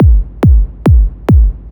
Kick 140-BPM 1.wav